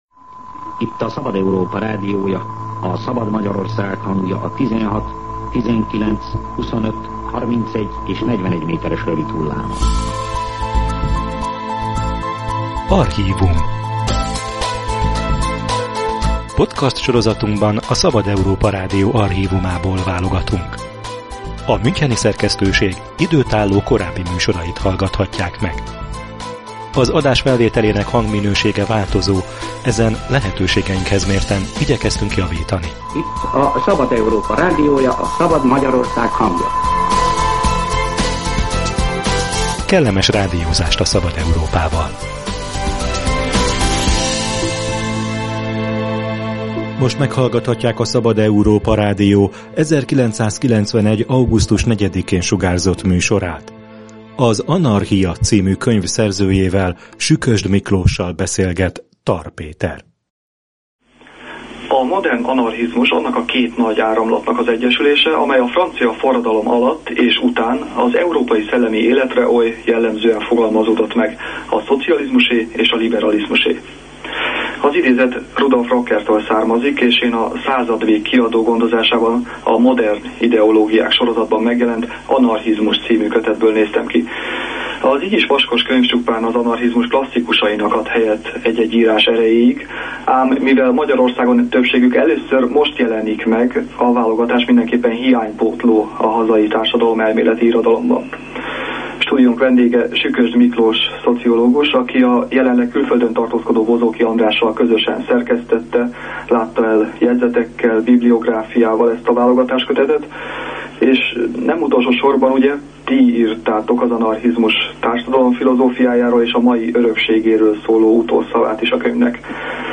Spontán rend és az egyéni jogok kiteljesedése – archív beszélgetés az anarchiáról